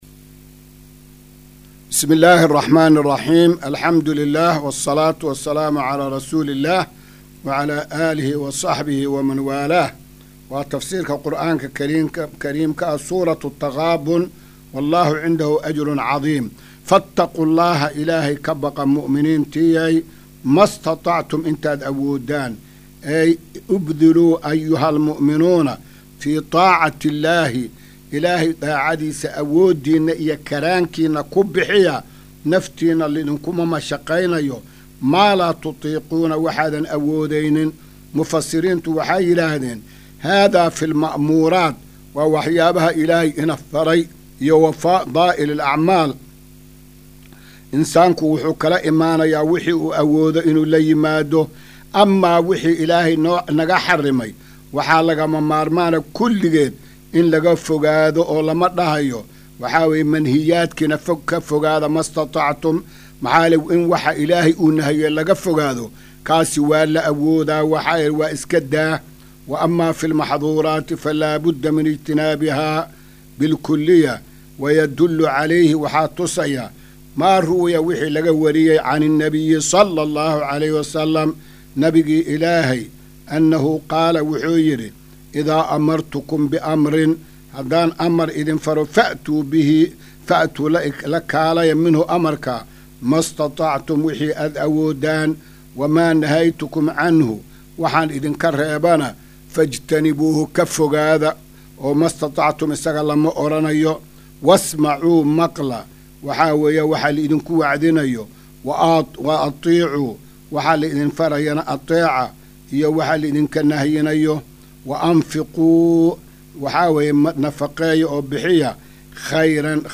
Maqal:- Casharka Tafsiirka Qur’aanka Idaacadda Himilo “Darsiga 266aad”